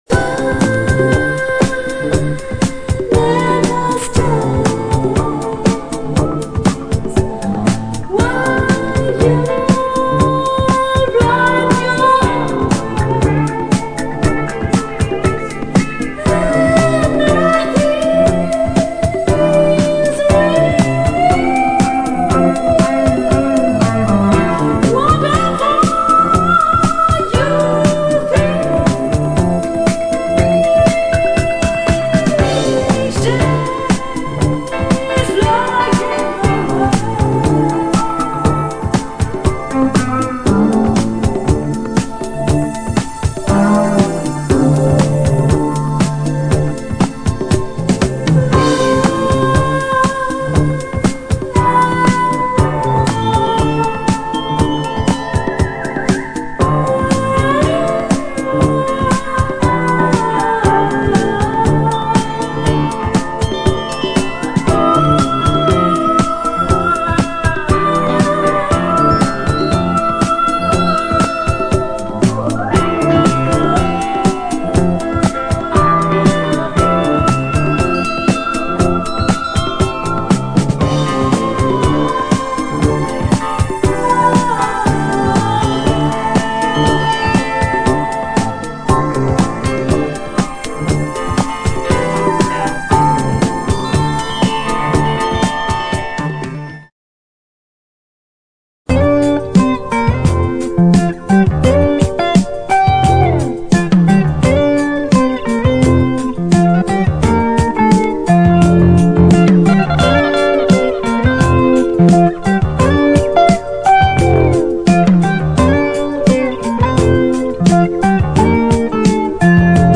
メロー・ギター・ソロが語るスローモ・フュージョン・ファンク
男女混合ヴォーカルのメロー・ソウル
フロート・シンセ漂うドリーミー・フュージョン・ダンサー